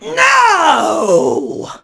pam_die_vo_01.wav